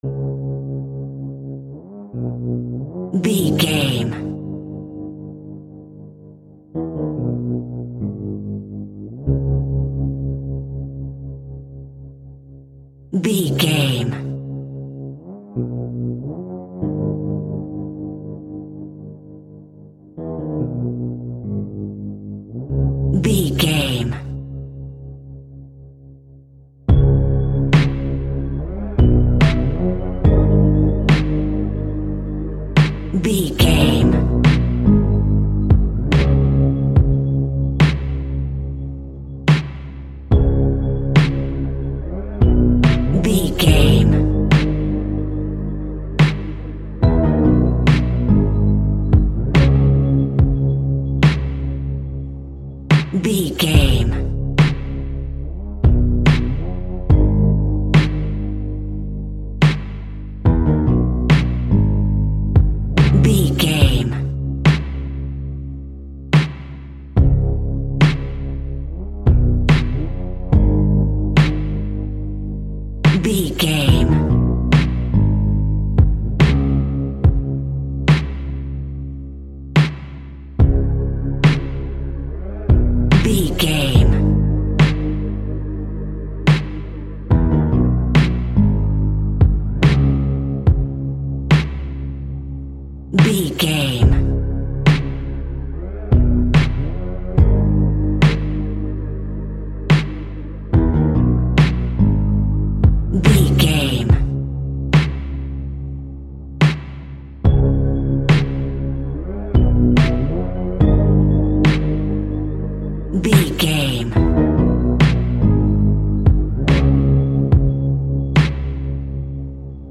Chilling Rap Instrumental.
Aeolian/Minor
C#
Slow
hip hop
laid back
hip hop drums
hip hop synths
piano
hip hop pads